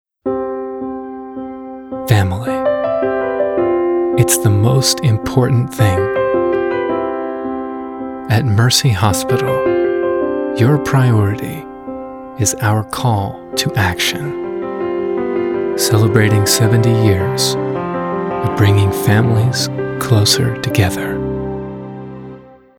Male
Radio Commercials
Guy Next Door, Storyteller
0516Mercy_Hospital_Demo.mp3